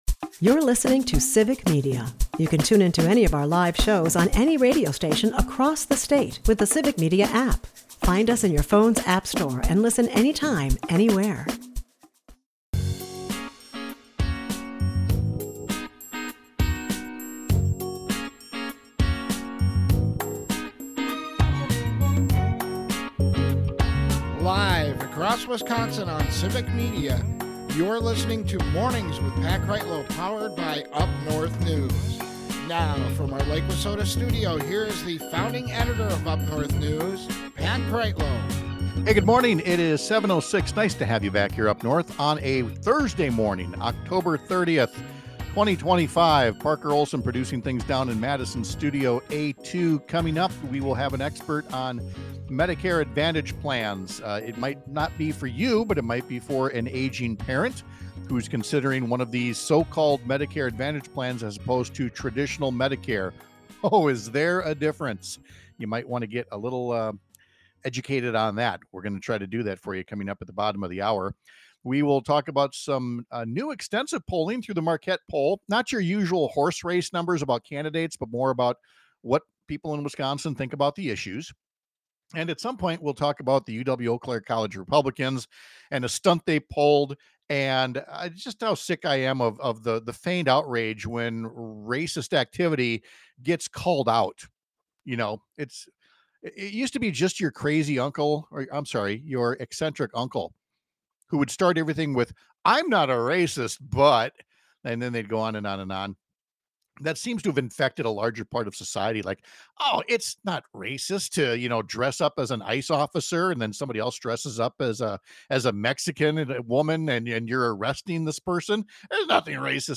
We’ll talk to an expert on so-called Medicare Advantage plans because we’re in the open enrollment season for seniors or their caregivers to make important choices on their health insurance plans for 2026.